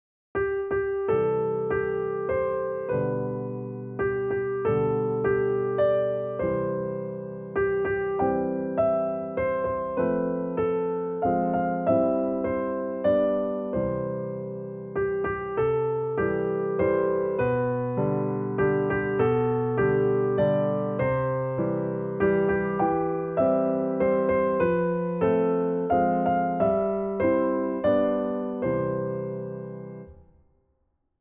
Hodně štěstí zdraví na klavír - videolekce a noty pro začátečníky